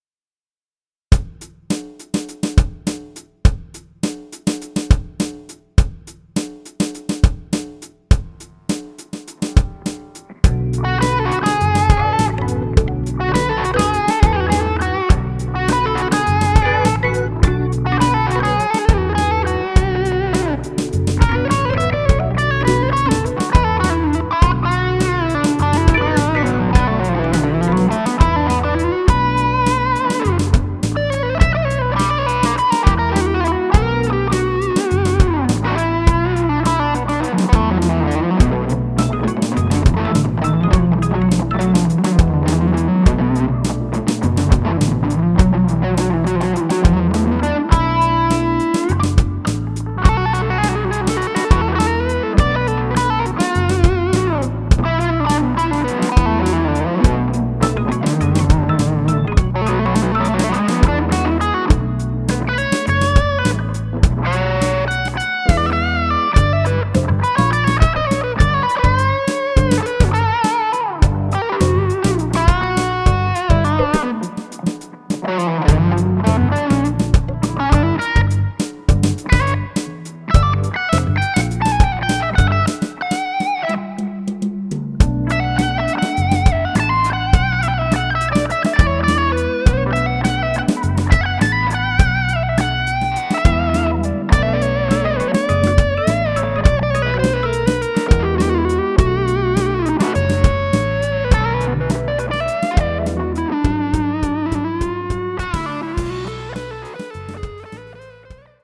Backing tracks collection
Μπηκα πριν λίγο σπίτι και ήθελα να δοκιμάσω ένα backing track και να παίξω λίγο διαφορετικά από το συνηθισμένο μου. scofLP.mp3 Βλακείες παίζω (δεν ξέρω τι κανω ακριβώς), αλλά δεν έχω λόγο να μην το διασκεδάζω....